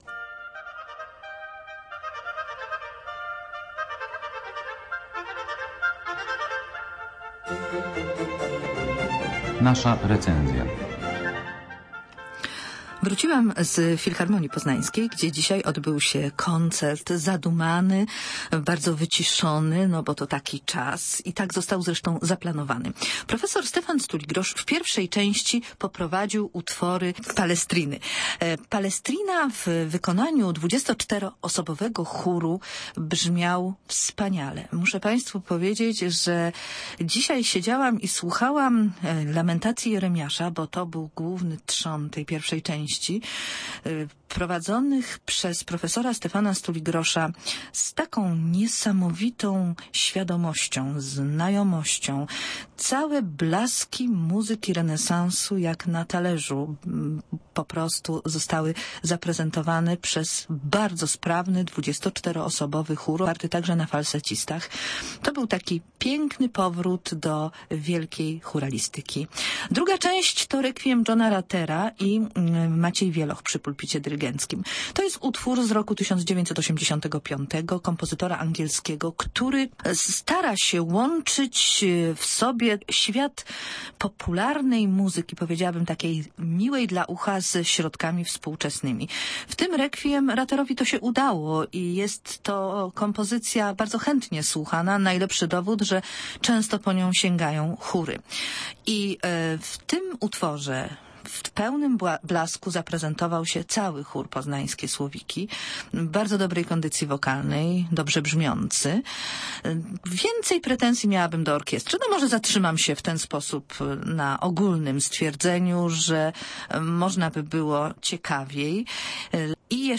W Auli UAM odbył się 29 października 406. Koncert Poznański "Requiem aeternam". Wystąpiły Poznańskie Słowiki i Orkiestra Filharmonii Poznańskiej.